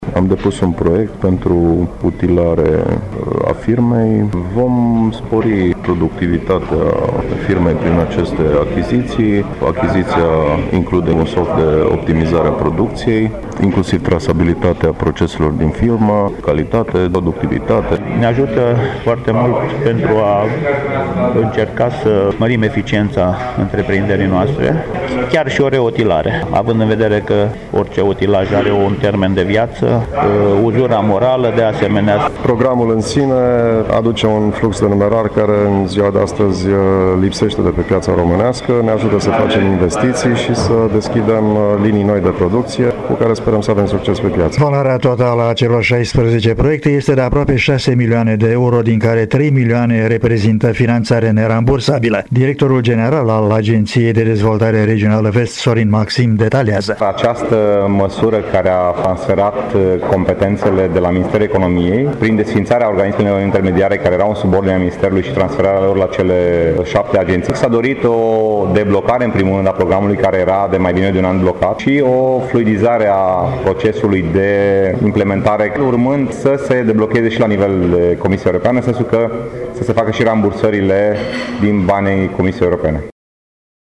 Corespondentul nostru